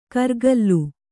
♪ kargallu